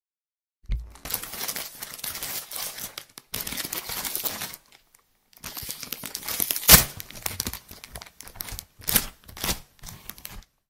Звук, сводящий кошек с ума шуршащий пакет